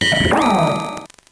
Cri de Lançargot dans Pokémon Noir et Blanc.